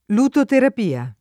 lutoterapia [ lutoterap & a ] s. f. (med.)